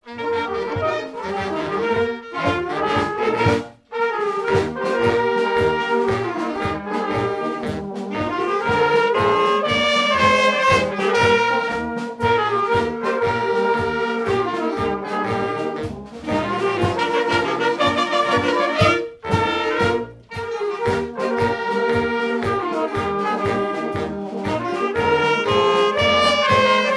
musique, ensemble musical
carnaval, mardi-gras
Pièce musicale inédite